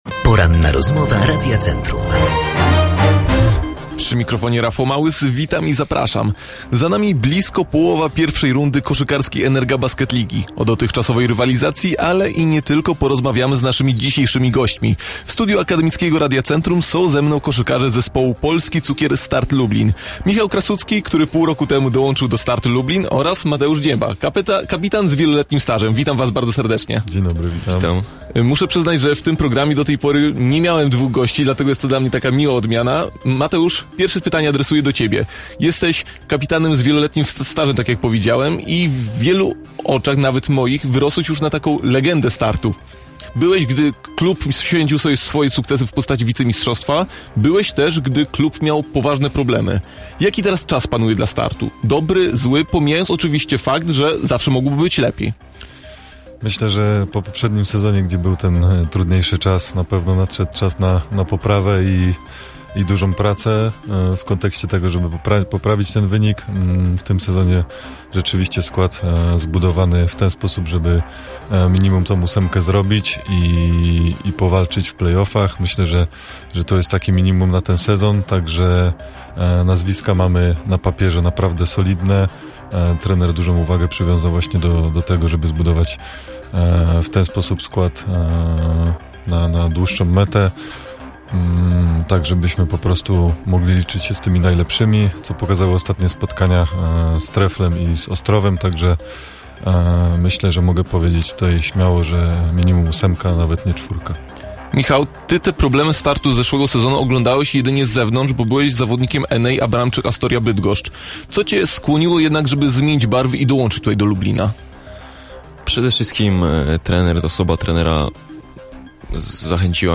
Rozmowa-po-edycji.mp3